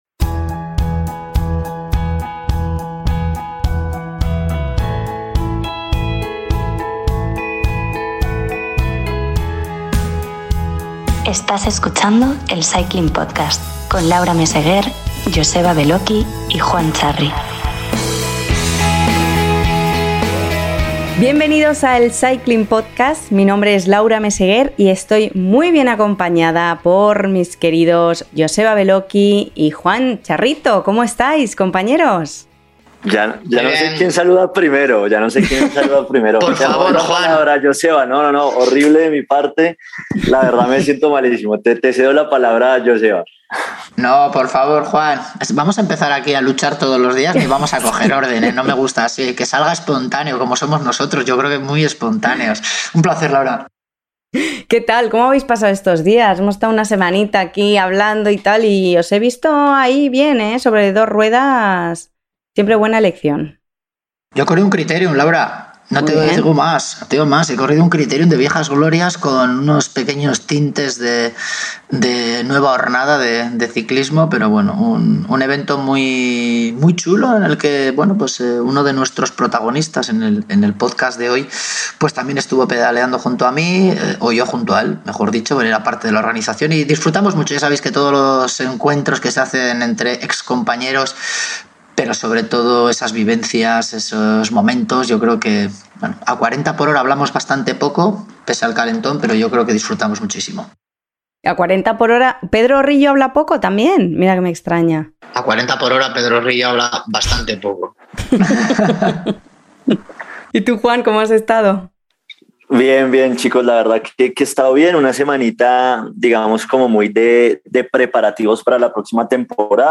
Cuentan con el honor de tener como invitado al ex ciclista colombiano Mauricio Soler, y el testimonio del campeón del mundo Igor Astarloa.